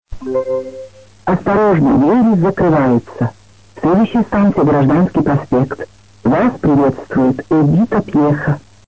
Еще пару праздничных объявлений в метро:
1. Э. Пьеха (Девяткино II путь) -